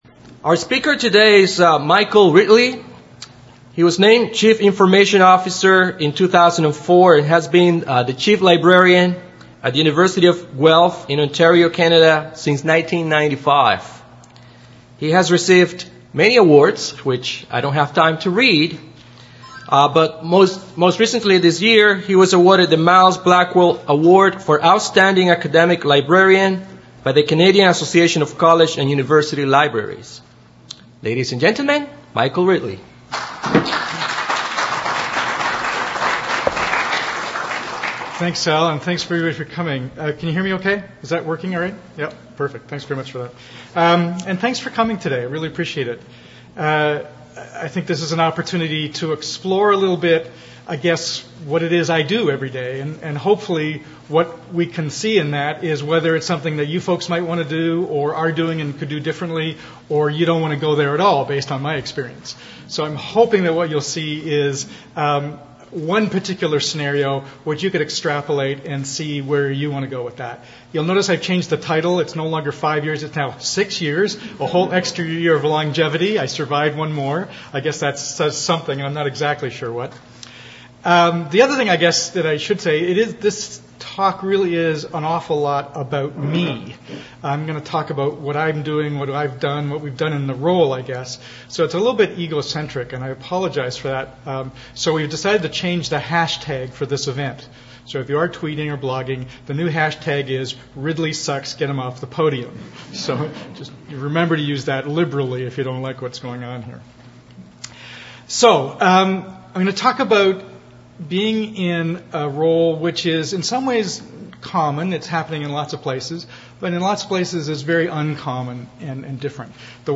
MP3 of Session